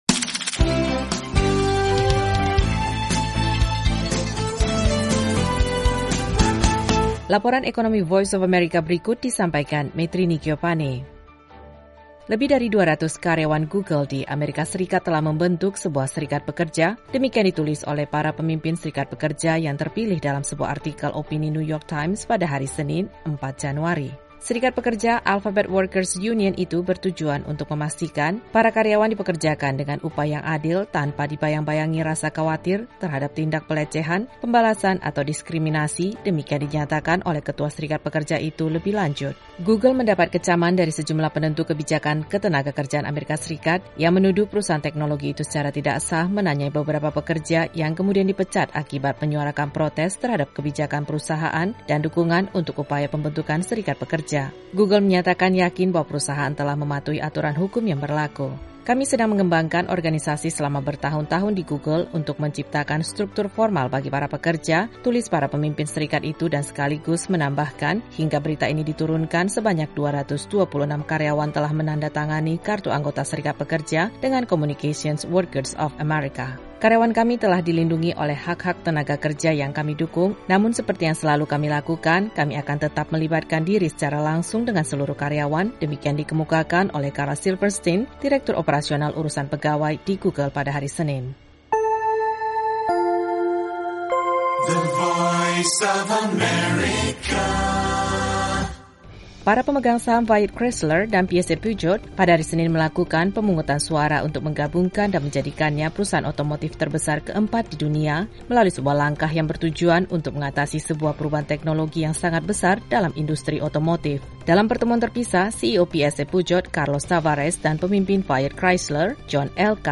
Laporan ekonomi VOA kali ini mengenai pembentukan serikat pekerja karyawan Google di Amerika Serikat dan merger perusahaan otomotif Fiat Chrysler dengan Peugeot.